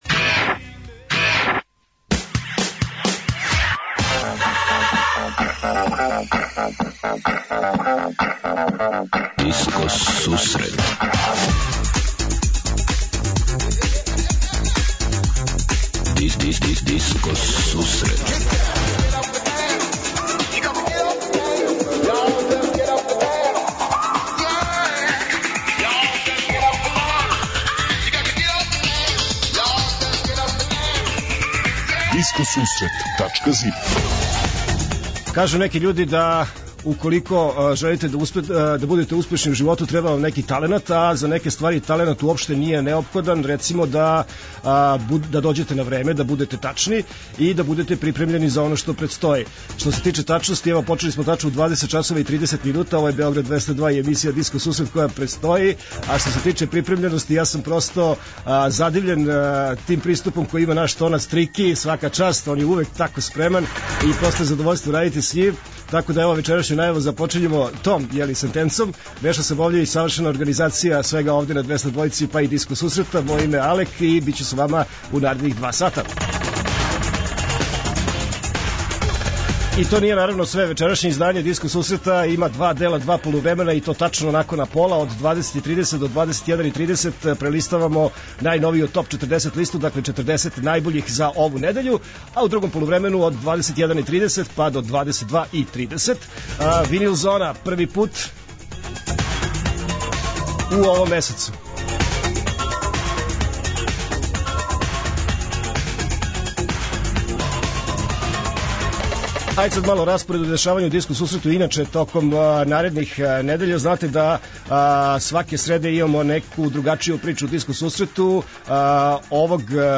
Од 20:30 Диско Сусрет Топ 40 - Топ листа 40 највећих светских диско хитова.
Од 21:30 Винил Зона - Слушаоци, пријатељи и уредници Диско Сусрета за вас пуштају музику са грамофонских плоча.
преузми : 28.29 MB Discoteca+ Autor: Београд 202 Discoteca+ је емисија посвећена најновијој и оригиналној диско музици у широком смислу, укључујући све стилске утицаје других музичких праваца - фанк, соул, РнБ, итало-диско, денс, поп.